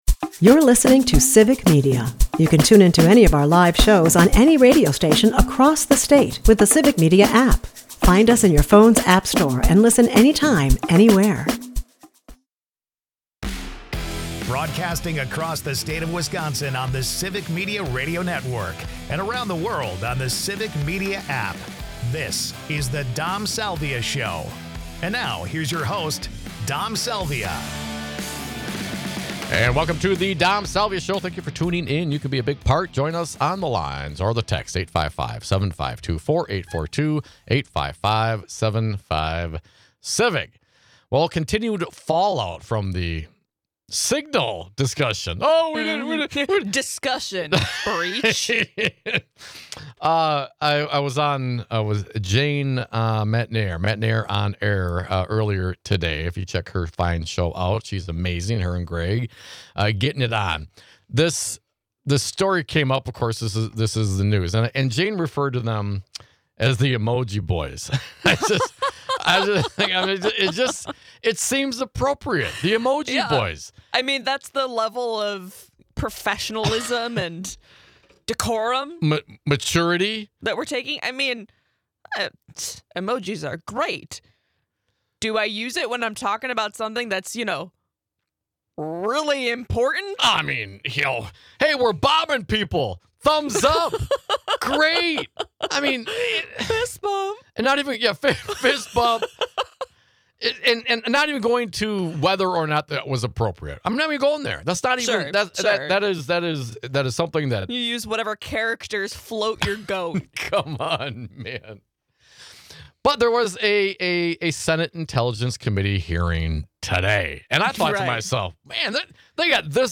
We share highlights featuring Senators Mark Warner and Jon Ossoff ; you can watch the full hearing here . We also share audio of Secretary of Defense Pete Hegseth completely denying the nature of the breach .